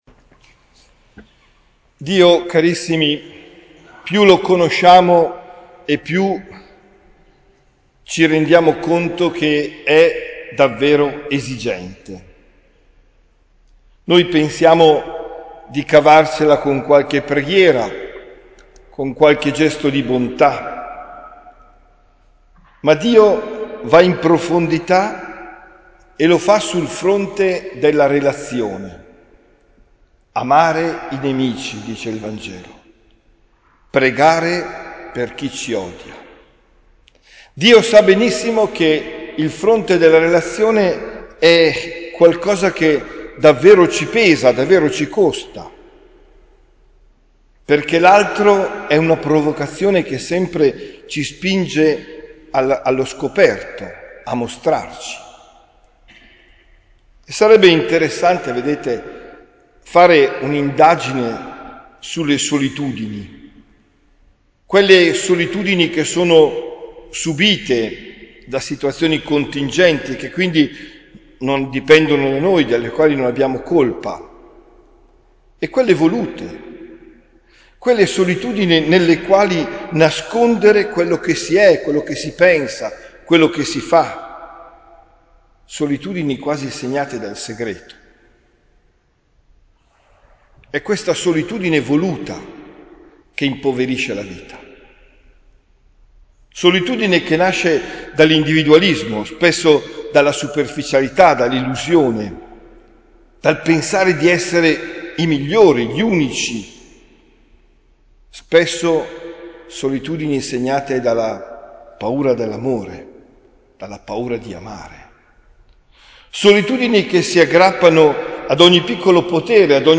OMELIA DEL 19 FEBBRAIO 2023